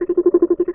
sci-fi_scan_target_04.wav